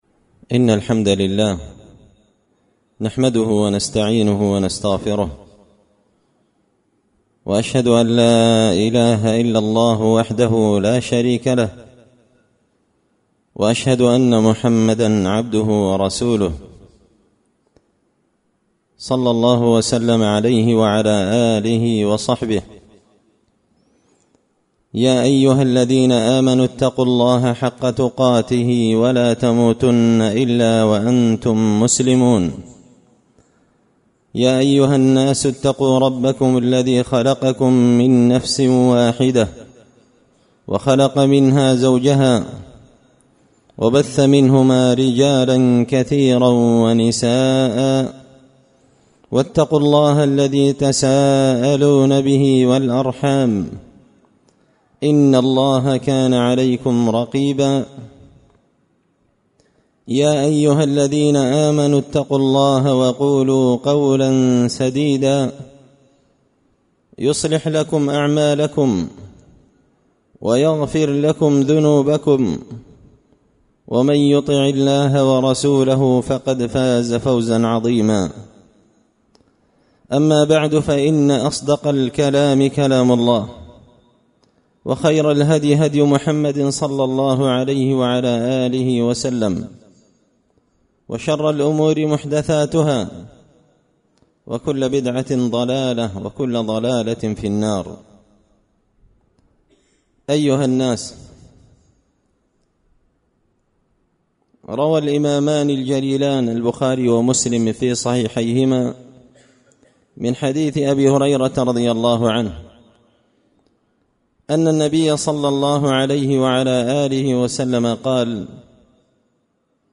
خطبة جمعة بعنوان – شدة الحر من فيح جهنم
دار الحديث بمسجد الفرقان ـ قشن ـ المهرة ـ اليمن